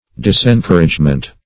Search Result for " disencouragement" : The Collaborative International Dictionary of English v.0.48: Disencouragement \Dis`en*cour"age*ment\, n. Discouragement.